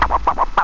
drum_kits
1 channel
.14Scratch_.mp3